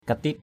/ka-ti:t/ (d.) con gián hôi = cancrelat.